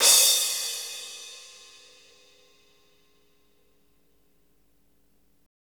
Index of /90_sSampleCDs/Roland - Rhythm Section/CYM_Cymbals 1/CYM_Cymbal menu
CYM CRASH04L.wav